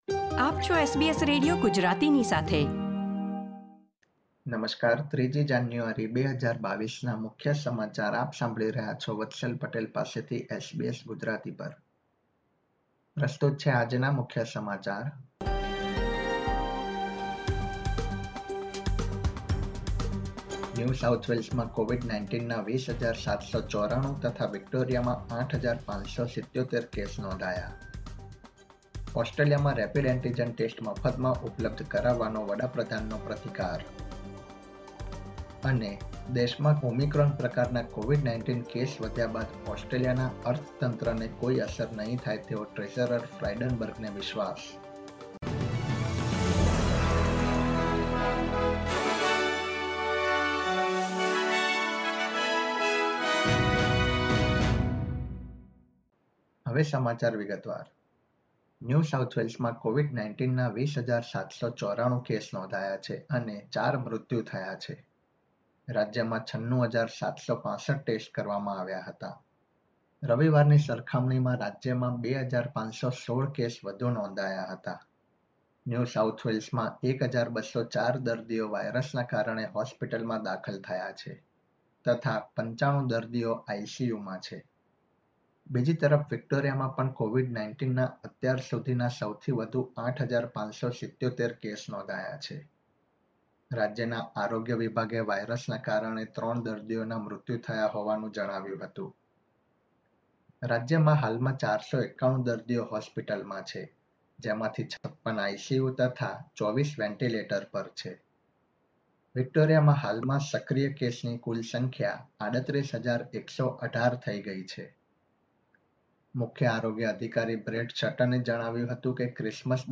SBS Gujarati News Bulletin 3 January 2022